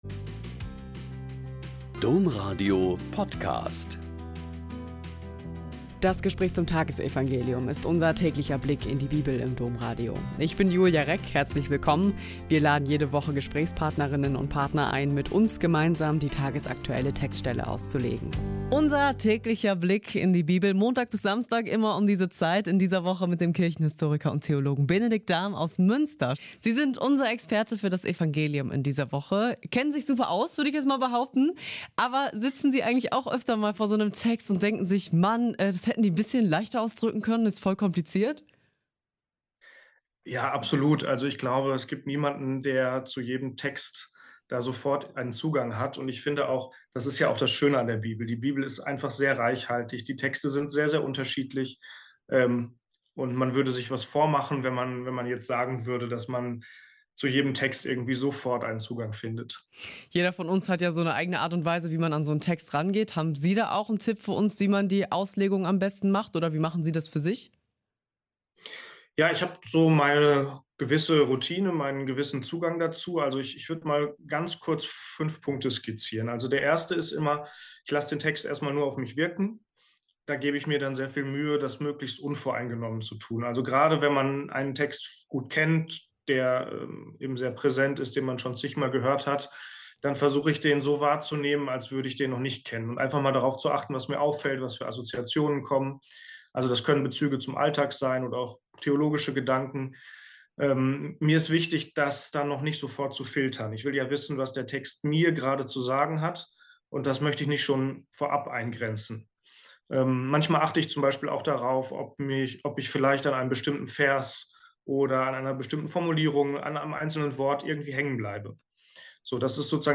Mt 13,54-58 - Gespräch